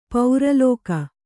♪ paura lōka